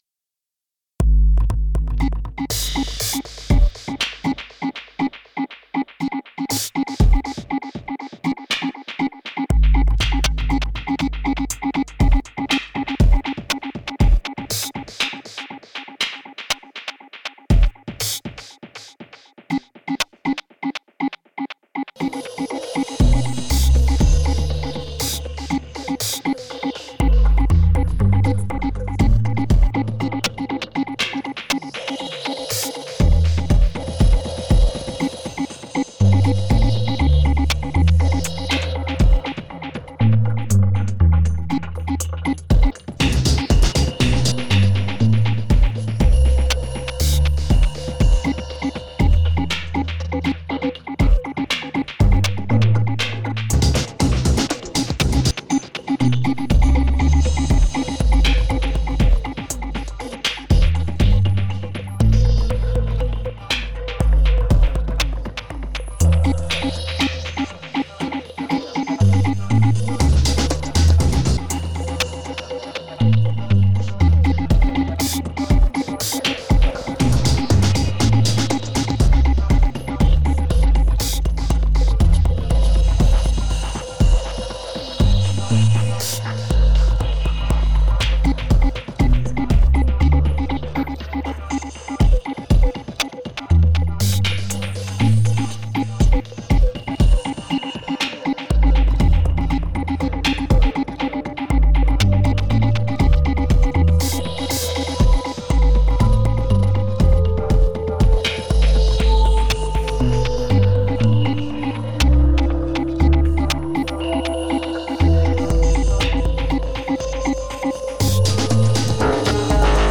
gorgeous and intricately crafted beats